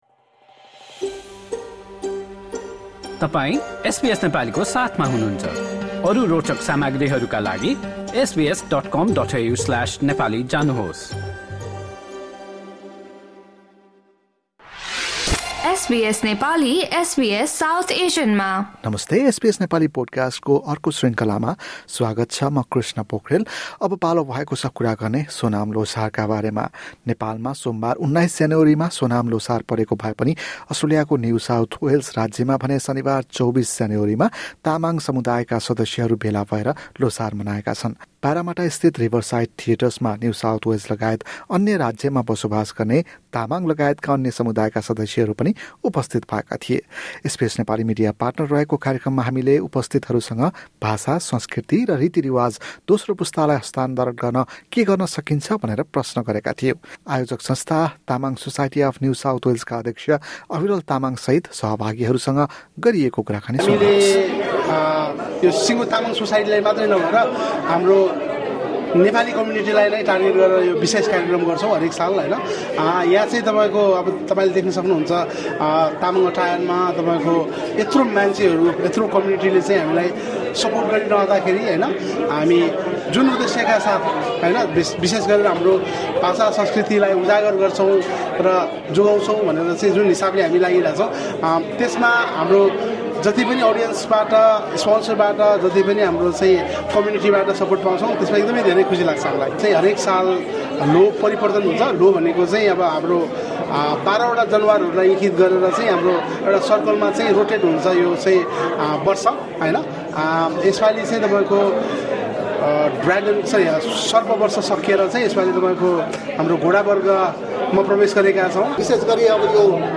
Members of the Tamang community from New South Wales and surrounding areas gathered at Riverside Theatres in Parramatta on Saturday, January 24, to celebrate Sonam Lhosar. SBS Nepali spoke to organisers and participants about what can be done to pass on the language and, culture to the second generation.